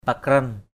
/pa-krʌn/ (cv.)